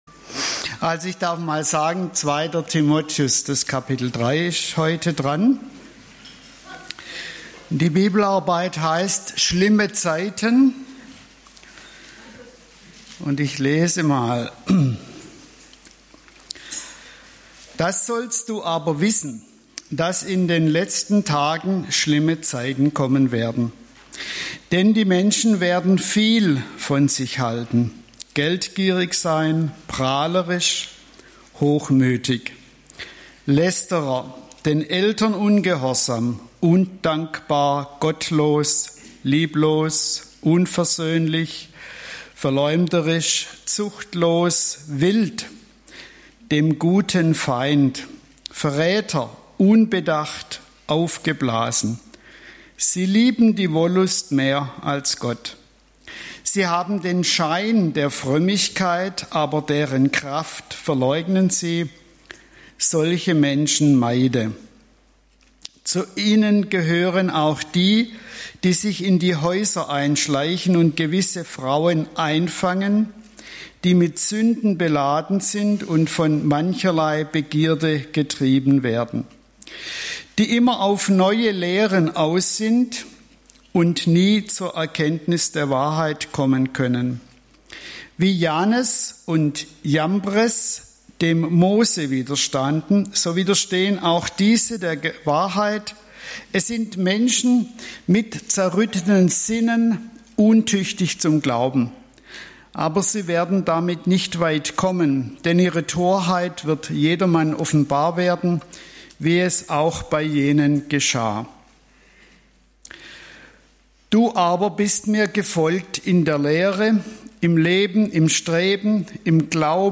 Gottesdienste und Bibelstunden der Langensteinbacher Höhe … continue reading 13 Episoden # Langensteinbacher Höhe # Christlich # Gesellschaft # Religion # Christentum